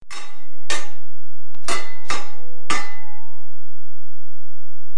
Sound clips: Swords Clang 5x 1
Two swords clang during sword fight (WAV file)
48k 24bit Stereo
Try preview above (pink tone added for copyright).
Tags: sword